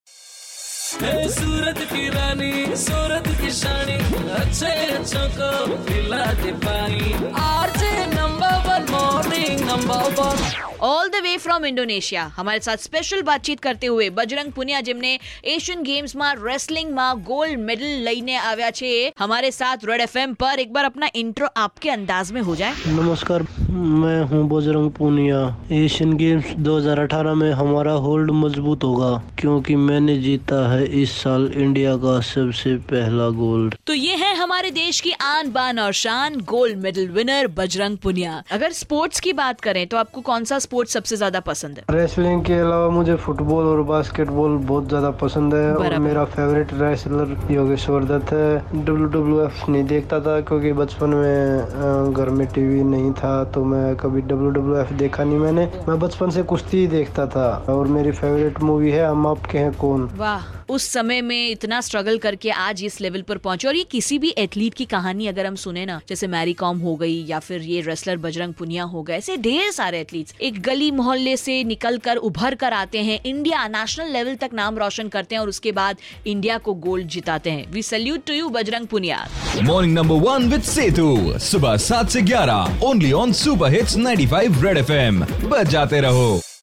IN CONVERSATION WITH BAJRANG PUNIYA